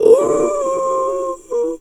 seal_walrus_2_death_04.wav